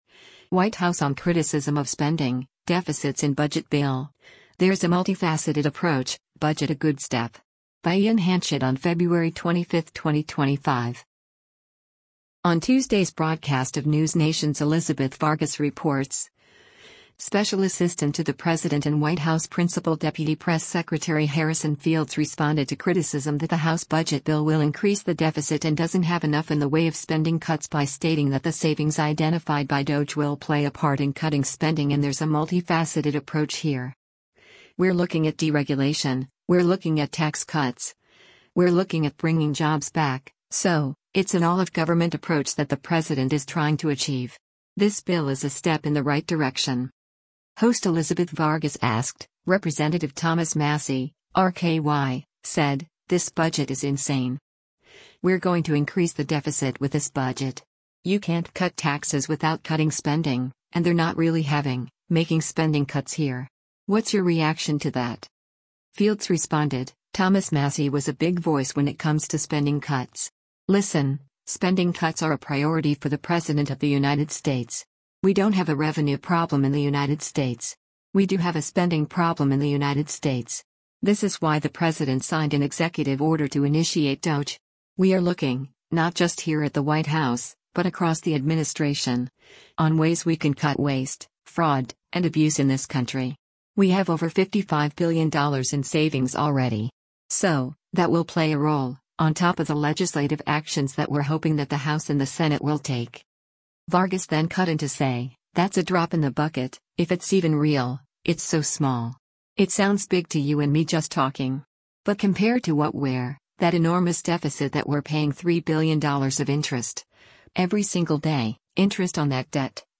On Tuesday’s broadcast of NewsNation’s “Elizabeth Vargas Reports,” Special Assistant to the President and White House Principal Deputy Press Secretary Harrison Fields responded to criticism that the House budget bill will increase the deficit and doesn’t have enough in the way of spending cuts by stating that the savings identified by DOGE will play a part in cutting spending and “there’s a multifaceted approach here.